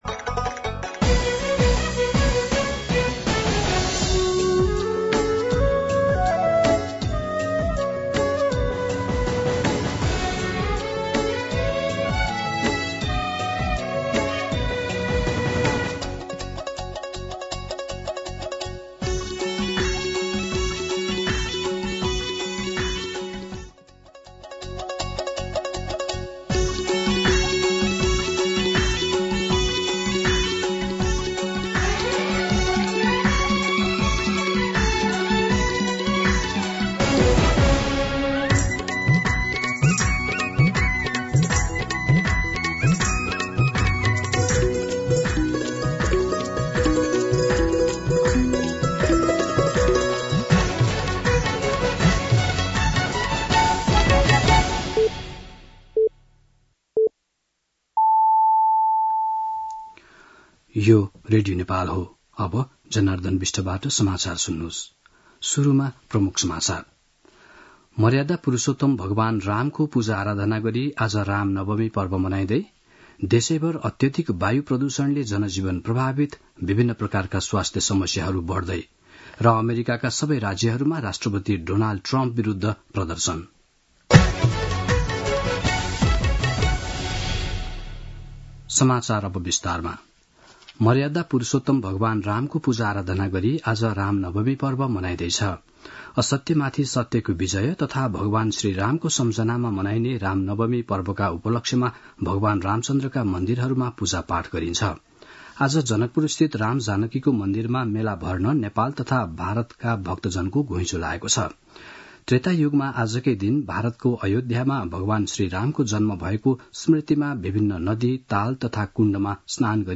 दिउँसो ३ बजेको नेपाली समाचार : २४ चैत , २०८१
3pm-news-.mp3